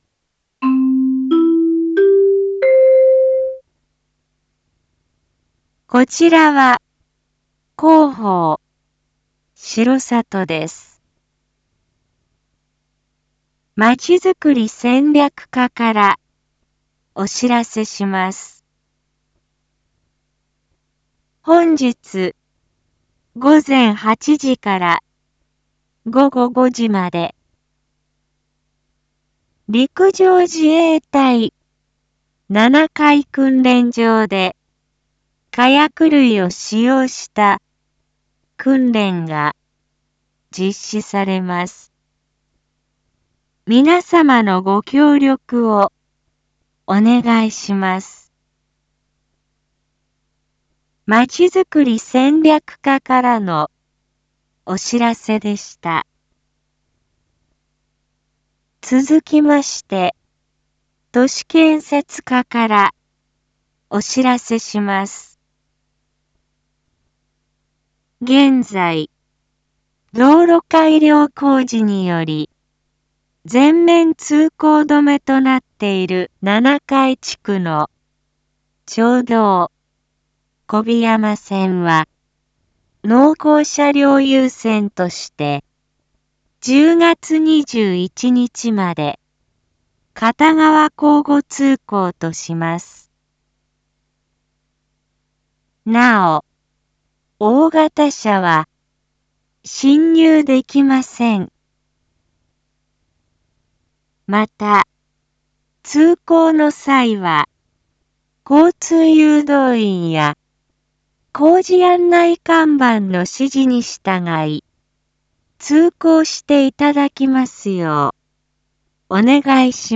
一般放送情報
Back Home 一般放送情報 音声放送 再生 一般放送情報 登録日時：2022-09-20 07:07:20 タイトル：陸上自衛隊七会訓練場・町道４号線交通規制について（七会地区限 インフォメーション：こちらは広報しろさとです。